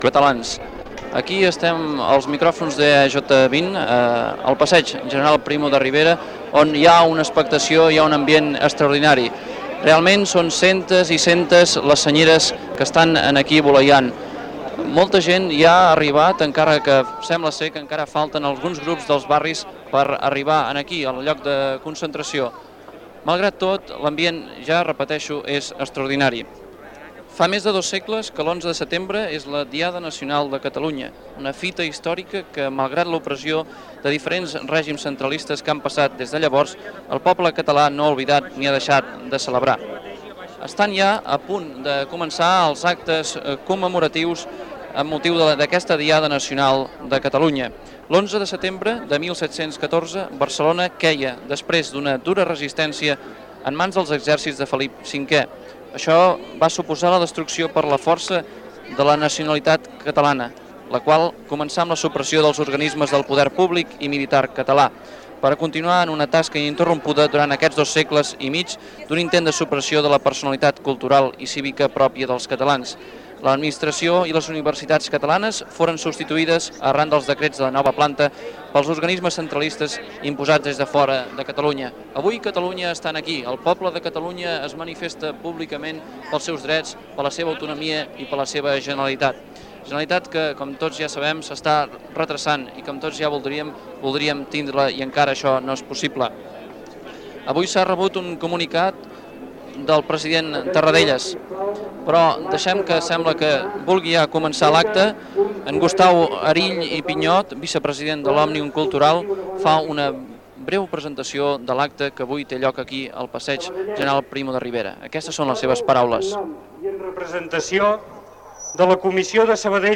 f6a217a0d82bf92befc723b36cd64f03a04cd027.mp3 Títol Ràdio Sabadell EAJ-20 Emissora Ràdio Sabadell EAJ-20 Titularitat Privada local Descripció Identificació de l'emissora, transmissió, des del Passeig General Primo de Rivera de Sabadell, de l'acte unitari commemoratiu de la Diada de Ctalunya de l'11 de setembre i reivindicatiu de l'Estatut d'Autonomia i de la reinstauració de la Generalitat.